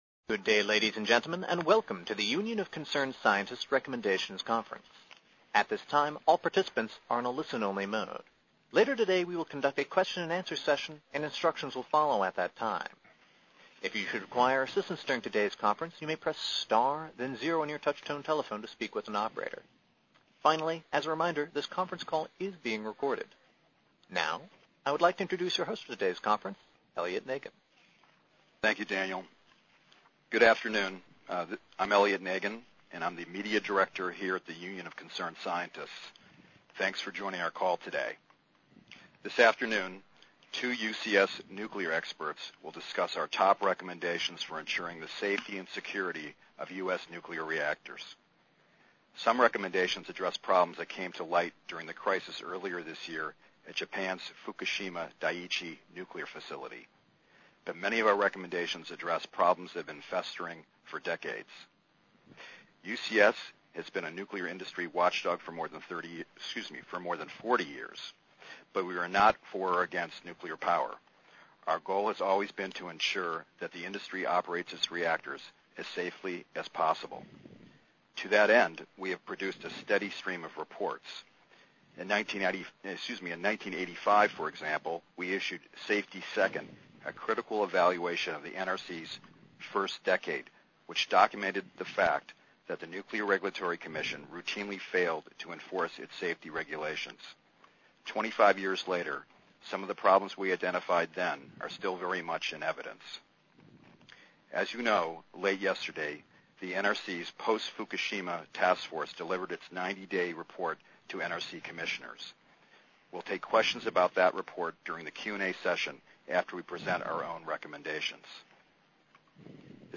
Transcript of Press Briefing on UCS Nuclear Power Recommendations